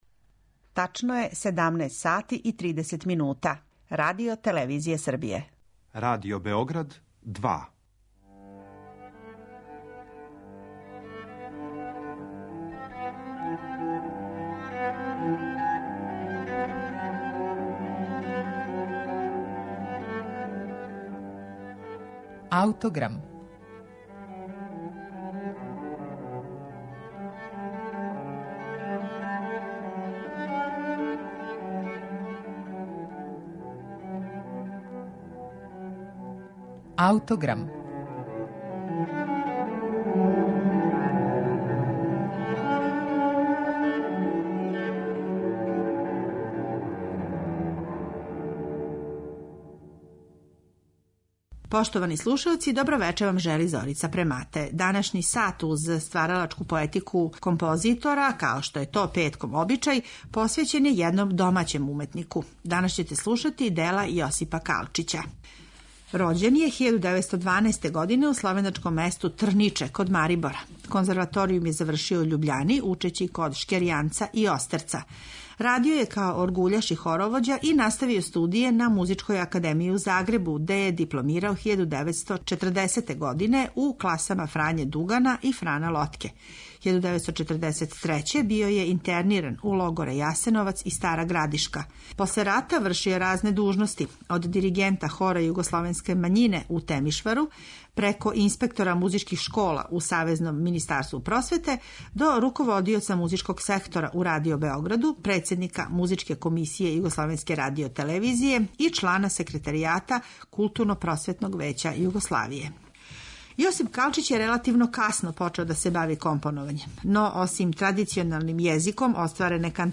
Ovoga puta pažnju smo posvetili jednom od naših zaboravljenih autora iz prošlog veka ‒ Josipu Kalčiću i njegovoj muzici za balet „Svetlost" iz 1976. godine. Na našem arhivskom snimku to delo izvode orkestar Beogradske filharmonije i dirigent Oskar Danon.